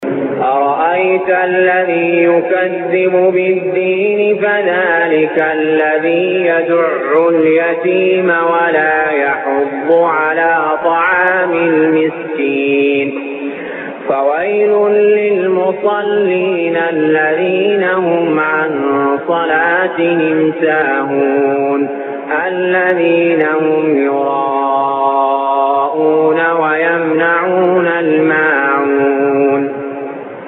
المكان: المسجد الحرام الشيخ: علي جابر رحمه الله علي جابر رحمه الله الماعون